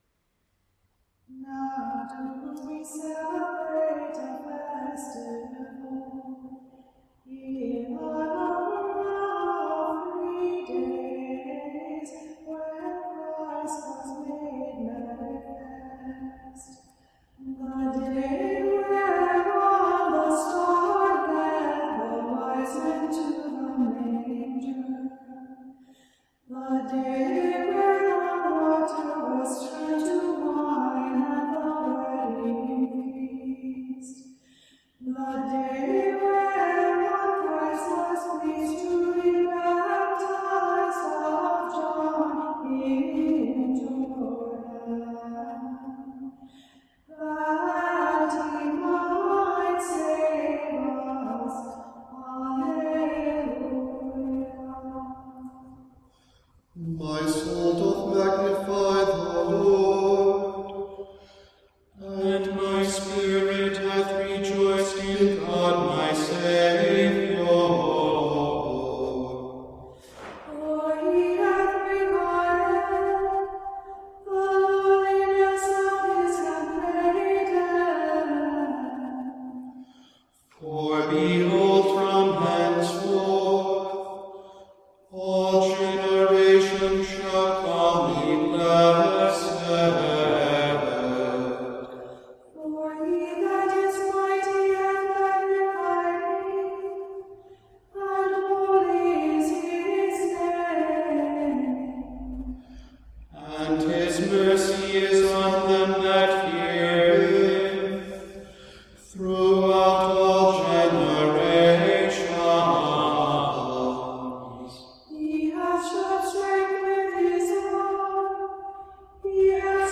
A few selections recorded by our choir (pieces marked with an * are from a CD recorded by singers from several Western Rite parishes).